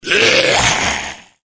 troll_mage_attack_2.wav